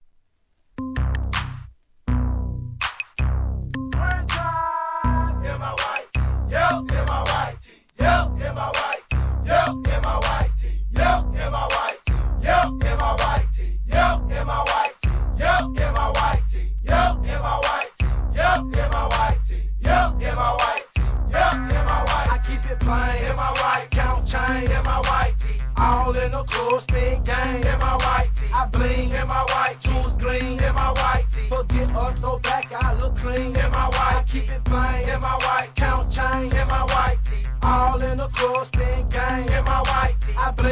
HIP HOP/R&B
ブリブリに低音の効いたトラックにコ−ル&レスポンスでフロア映え!